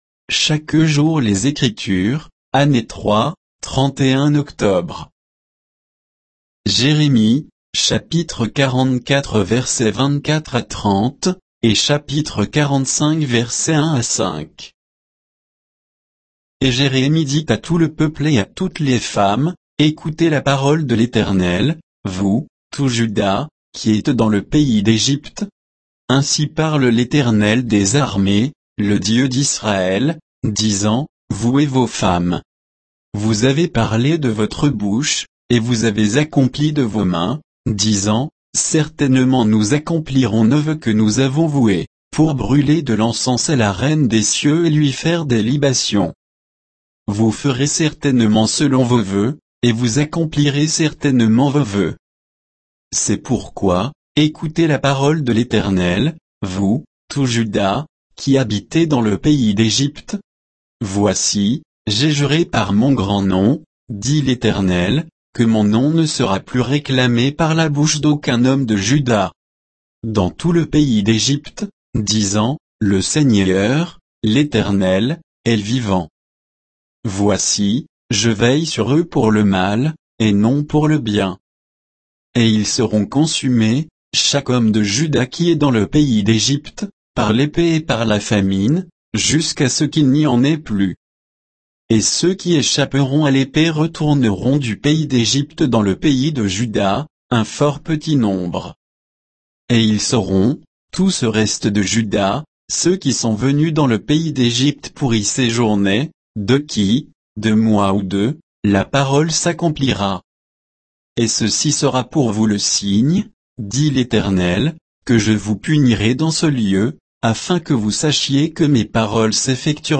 Méditation quoditienne de Chaque jour les Écritures sur Jérémie 44, 24 à 45, 5